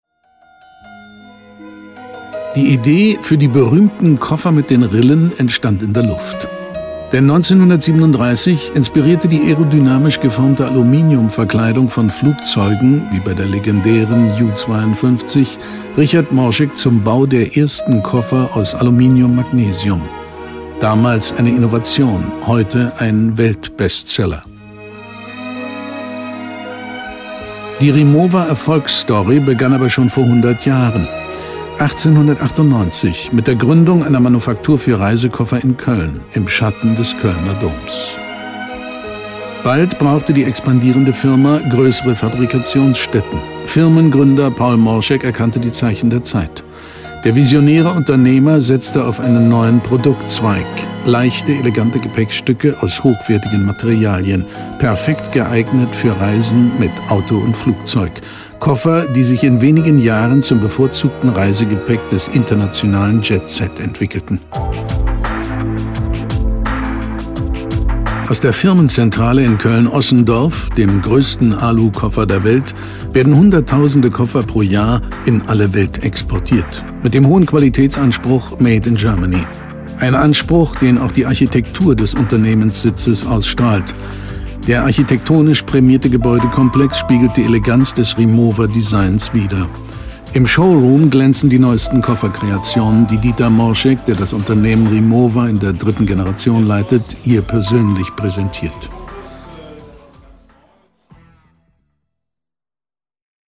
Vor laufender Kamera spielte Rolf Schult eher selten, doch seine unverwechselbar markante Stimme war in zahlreichen Fernsehproduktionen zu hören.
Du hast uns mit deiner charismatischen Stimme durch unser Leben begleitet, Du warst Regisseur der Bilder in unserem Kopfkino.